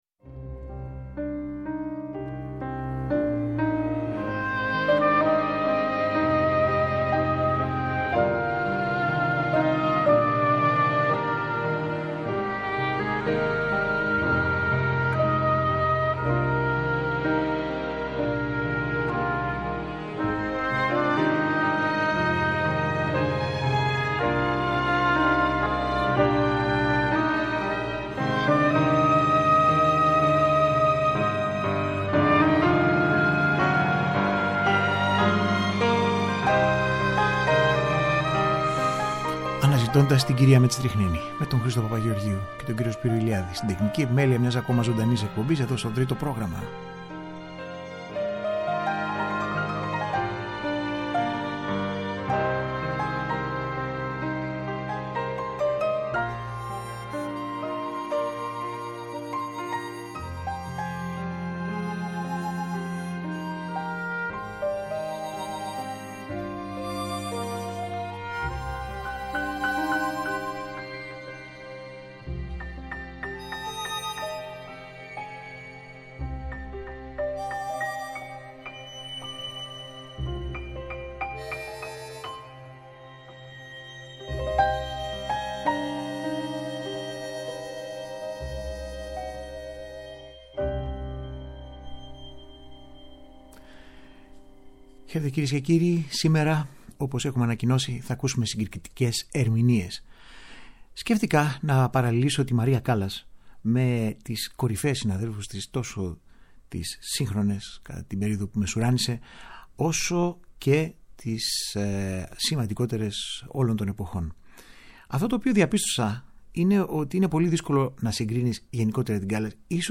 Μία σειρά πέντε ωριαίων εκπομπών με ηχογραφήσεις και αφηγήσεις όπως αυτές αποτυπώνονται μέσα από την πλούσια βιβλιογραφία για την μεγάλη αυτή προσωπικότητα της κοινωνικής ζωής και της τέχνης του 20ου αιώνα.